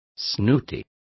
Complete with pronunciation of the translation of snooty.